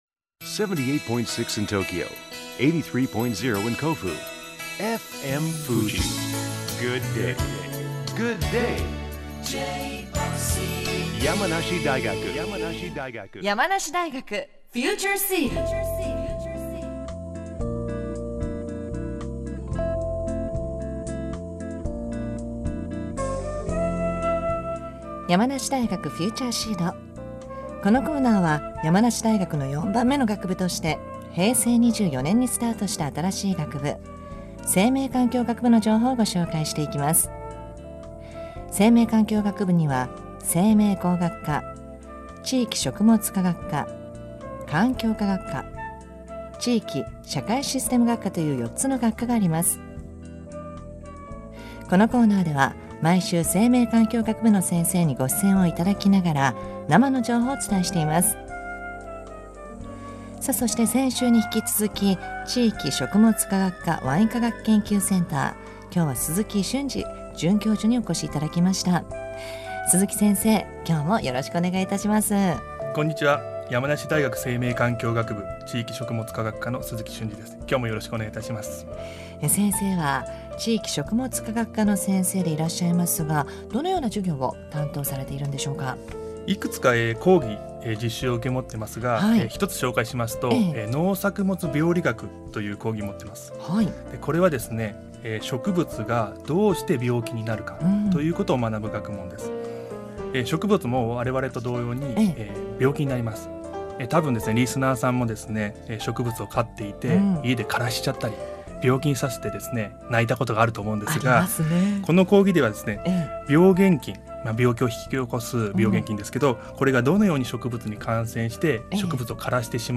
毎週木曜日11時10分頃から放送中の「山梨大学 FUTURE SEED」本日放送したものはこちらからお聞きいただけます。